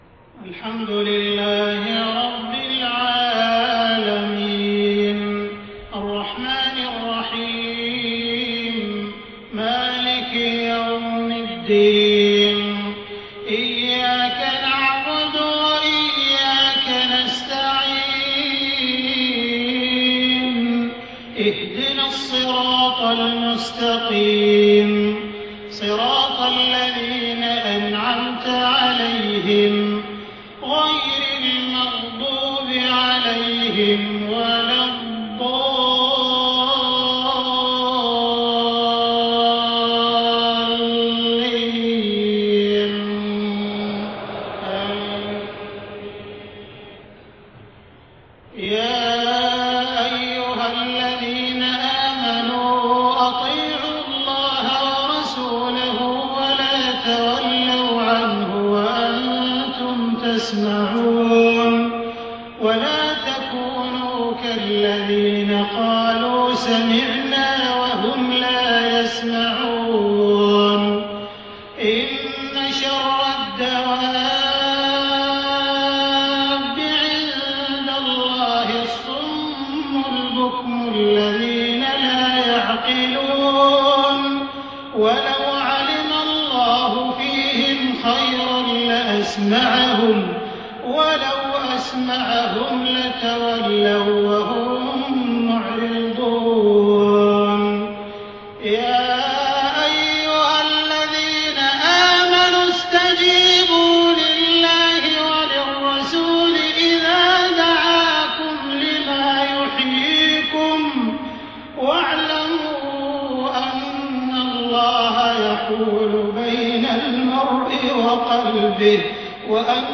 صلاة المغرب 28 محرم 1430هـ من سورة الأنفال 20-30 > 1430 🕋 > الفروض - تلاوات الحرمين